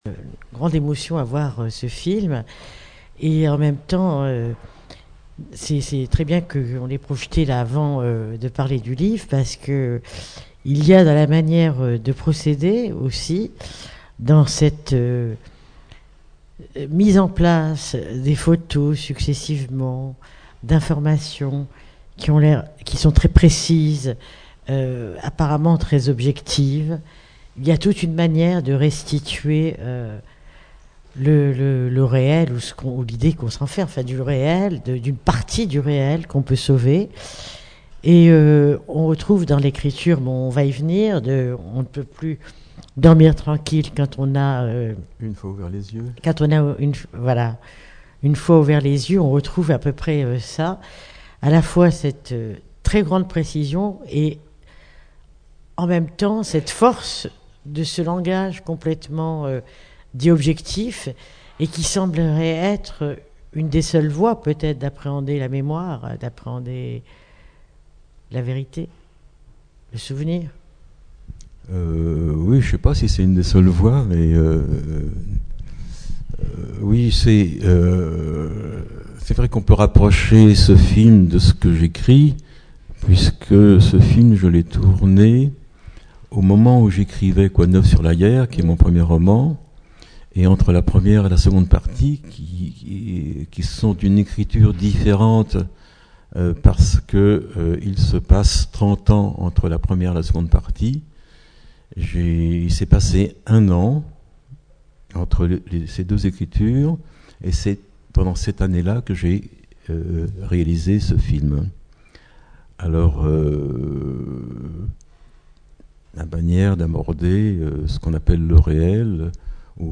Rencontre avec l’écrivain et réalisateur Robert Bober
Bober, Robert (1931-....). Personne interviewée
Rencontre littéraire